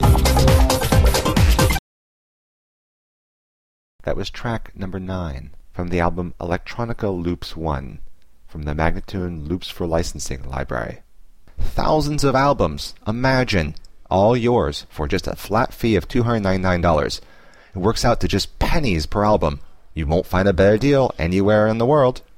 135-C-ambient:teknology-1031